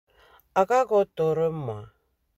Lecture et prononciation
Lisez les phrases suivantes à haute voix, puis cliquez sur l'audio pour savoir si votre prononciation est la bonne.